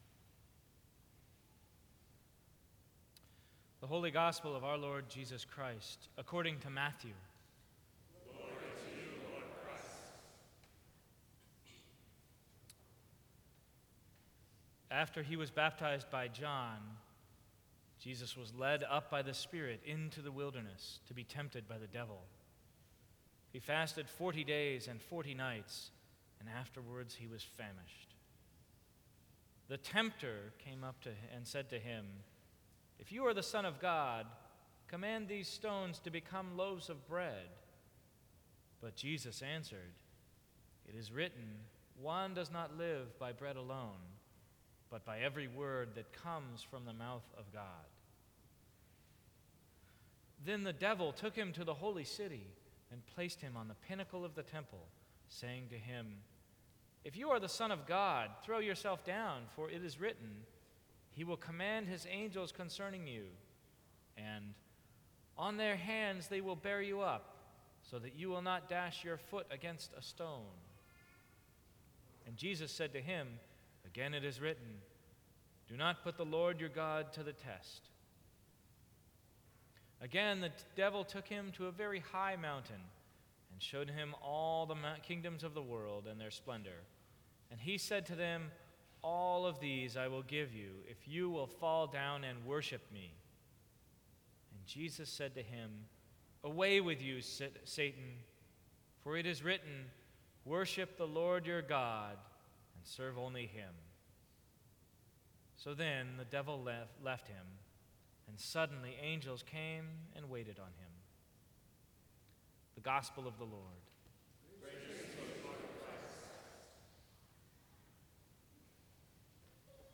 Sermons from St. Cross Episcopal Church March 9, 2014.